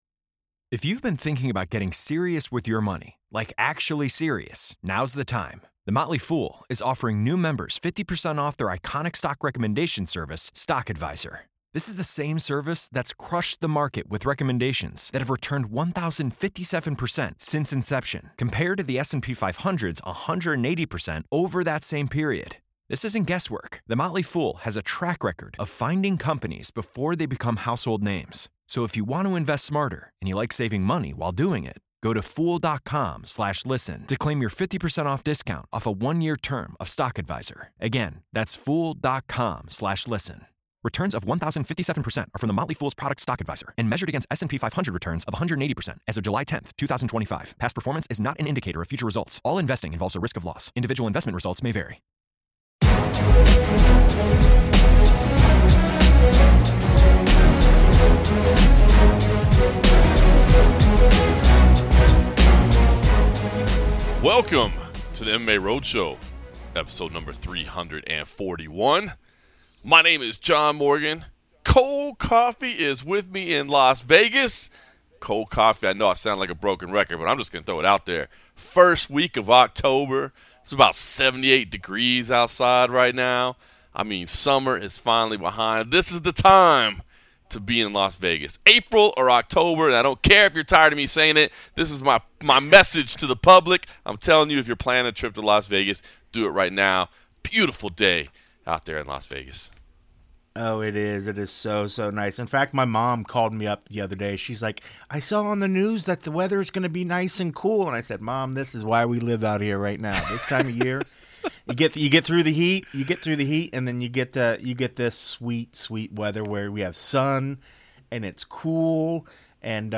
Along the way, hear from UFC headliner Mackenzie Dern, as well as top UFC welterweight Vicente Luque.